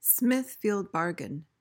PRONUNCIATION:
(SMITH-feeld bar-guhn)